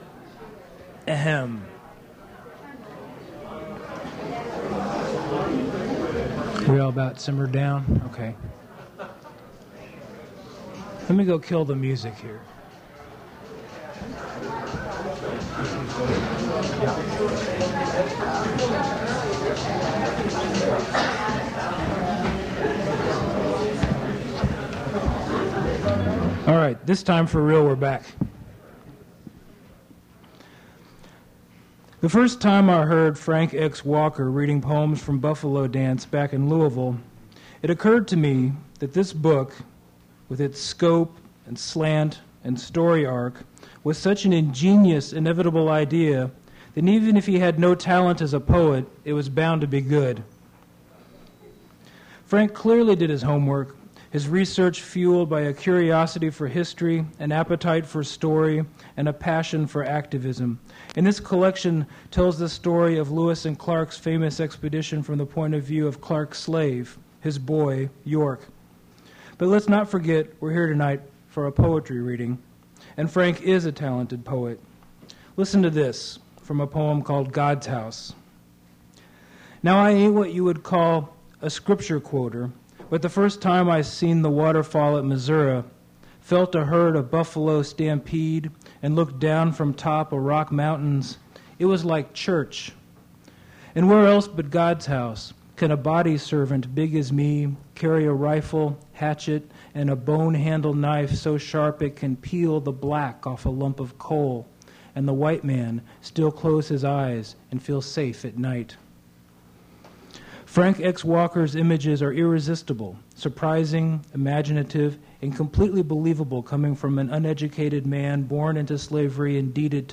Poetry reading featuring Frank X. Walker
Attributes Attribute Name Values Description Frank X. Walker poetry reading at Duff's Restaurant.
mp3 edited access file was created from unedited access file which was sourced from preservation WAV file that was generated from original audio cassette.